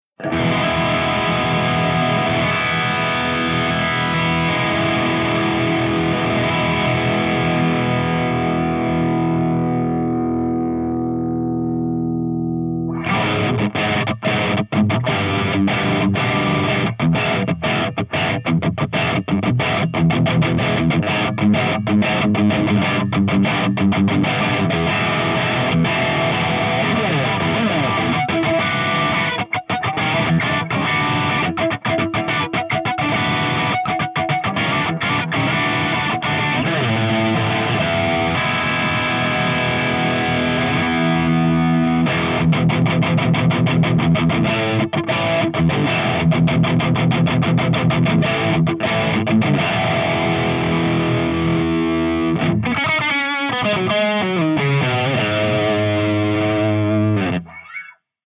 162_EVH5150_CH3DRIVE_V30_SC